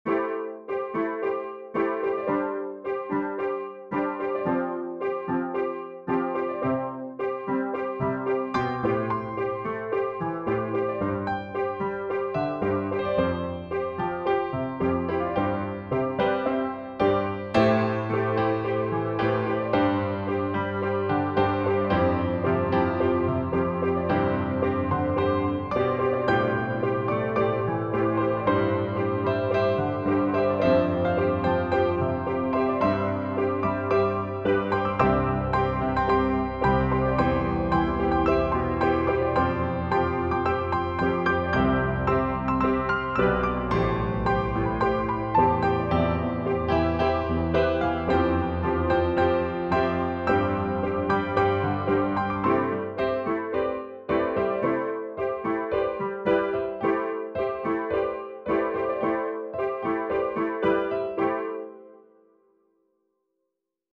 どこか懐かしい夏 ピアノ 幻想的 疾走感